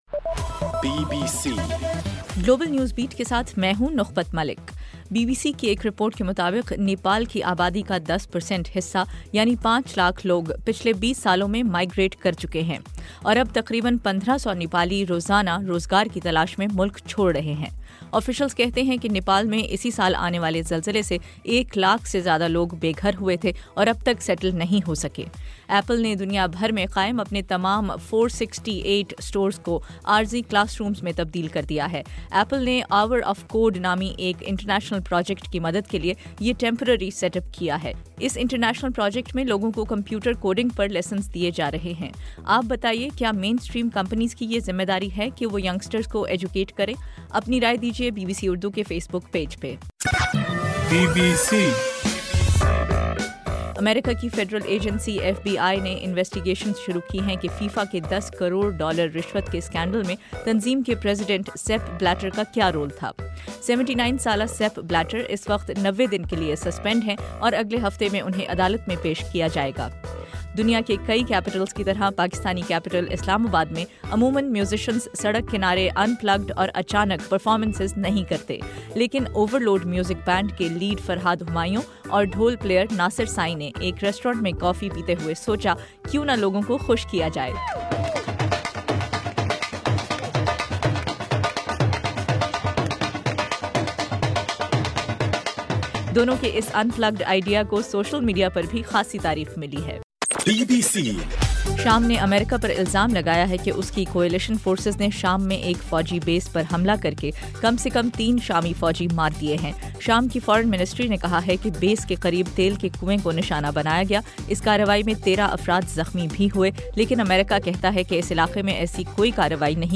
دسمبر 7: رات 10بجے کا گلوبل نیوز بیٹ بُلیٹن